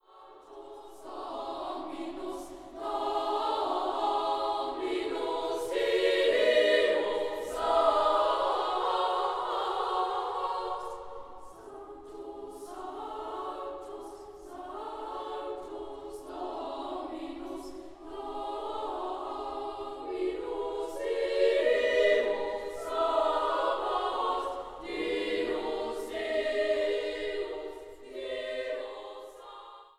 in Mitschnitten der Uraufführungen
für Chor a cappella – 2. Gloria